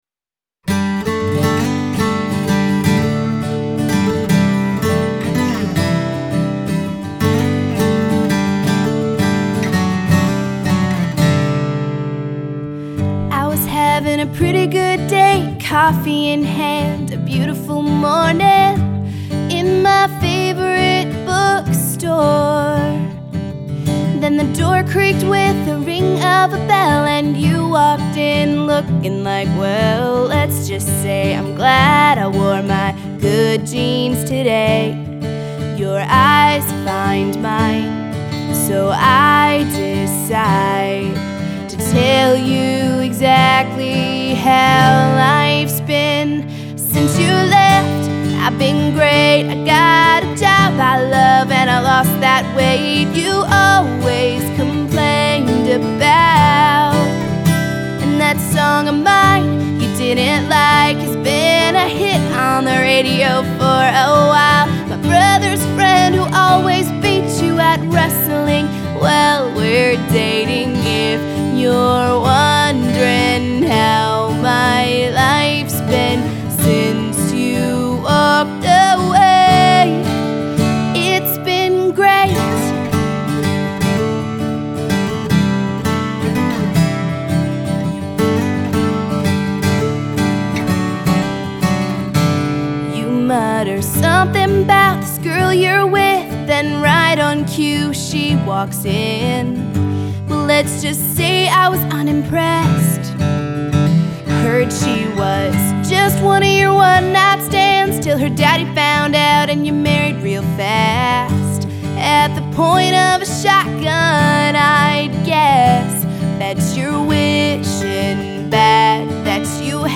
country singer-songwriter